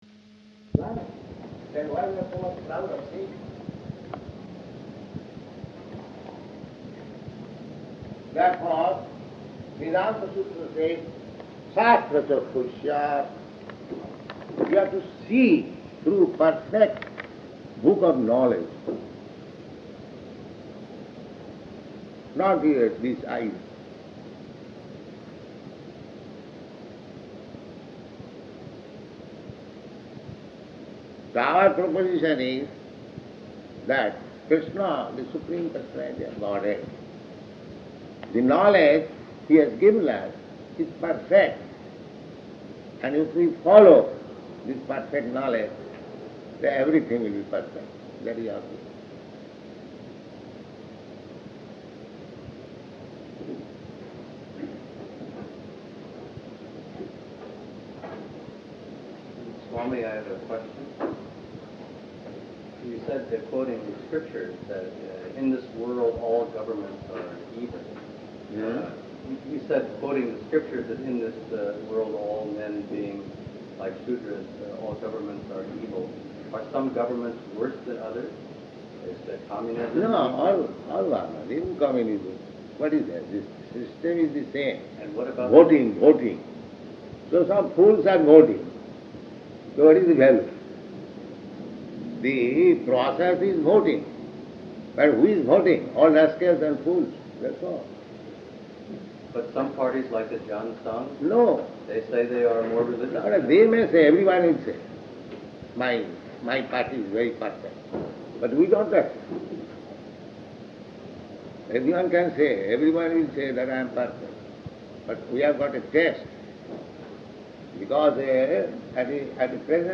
Room Conversation
Room Conversation --:-- --:-- Type: Conversation Dated: October 6th 1972 Location: Berkeley Audio file: 721006R1.BER.mp3 Prabhupāda: [indistinct] then why you are so much proud of seeing?